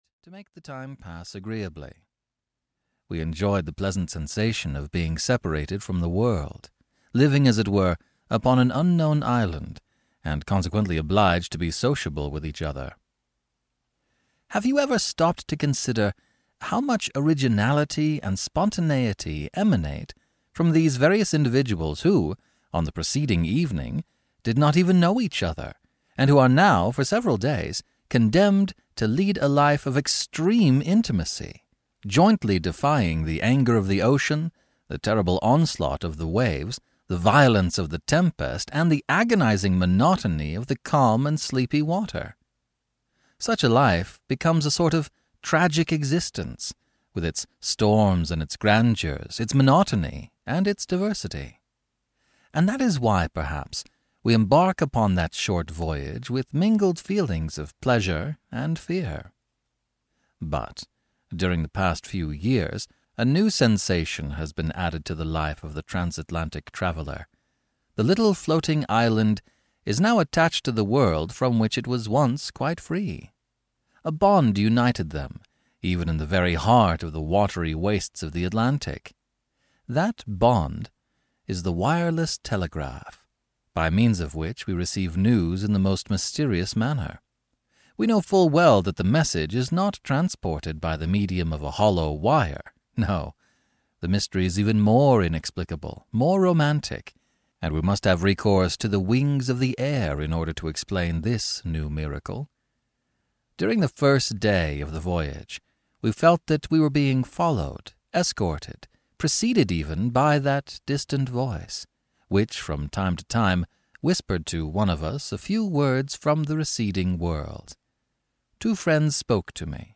Audio knihaThe Extraordinary Adventures of Arsene Lupin, Gentleman Burglar (EN)
Ukázka z knihy